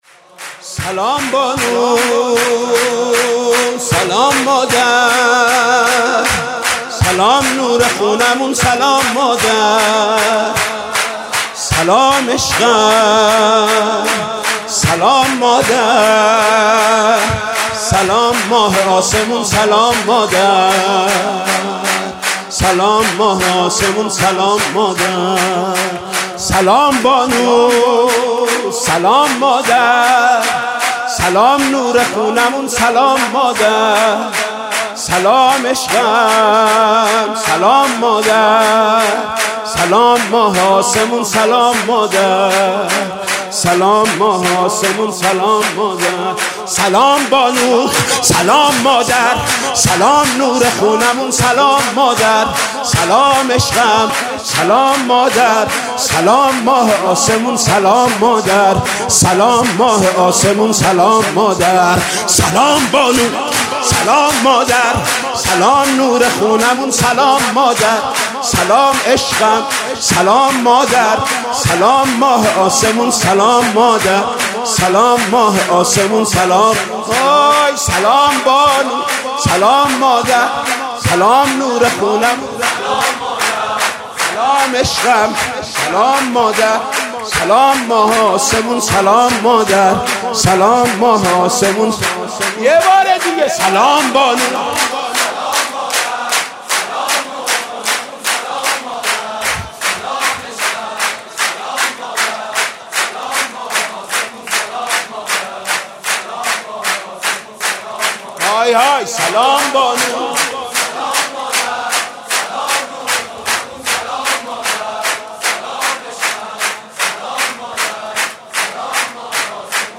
مولودی ولادت حضرت فاطمه زهرا